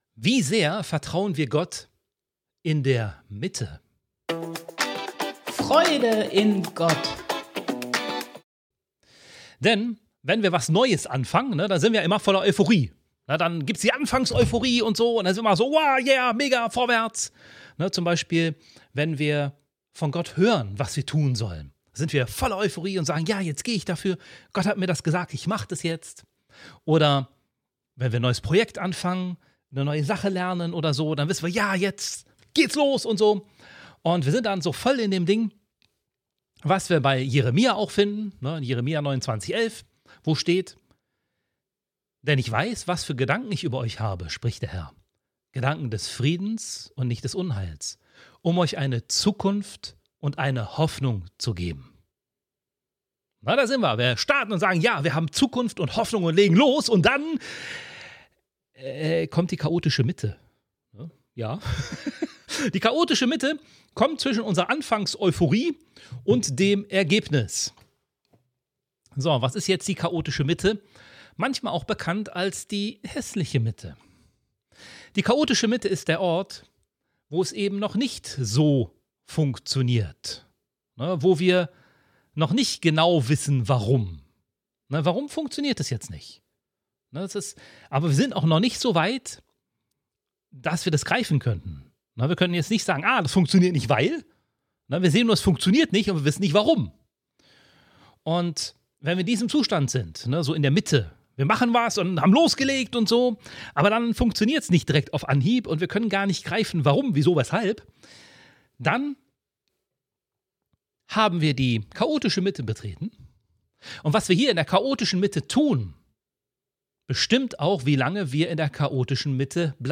Extra für dich: Am Ende der Folge sprechen wir ein gemeinsames Gebet, um Mangel und Chaos im Namen von Jesus Christus den Platz zu verweisen.